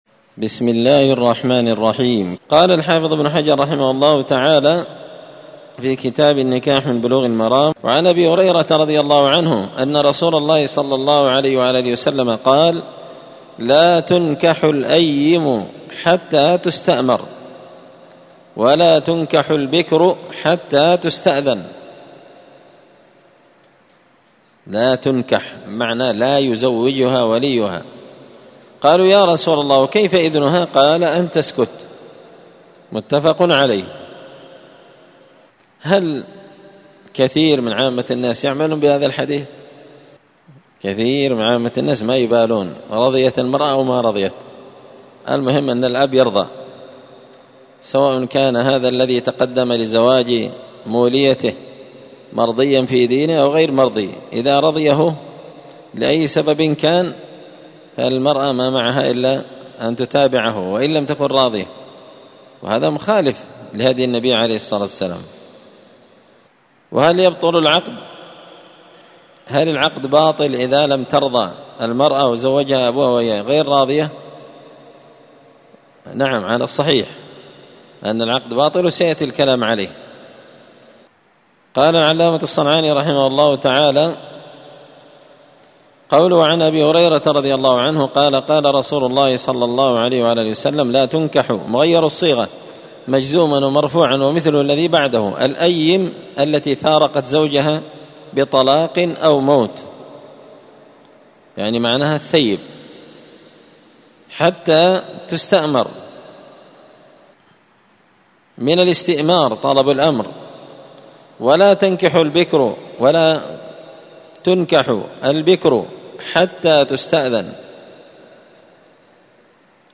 كتاب النكاح من سبل السلام شرح بلوغ المرام لابن الأمير الصنعاني رحمه الله تعالى الدرس – 8 تابع أحكام النكاح